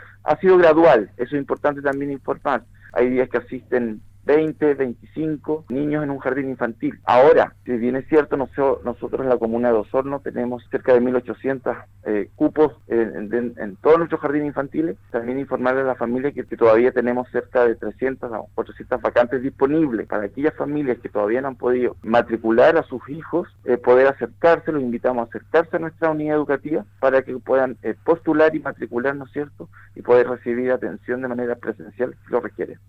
En entrevista con Radio Sago, el director regional de la Junji Los Lagos Eduardo Hernández, se refirió al retorno a los recintos preescolares en comunas que ya no se encuentran en un estado de cuarentena.